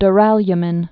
(d-rălyə-mĭn, dy-)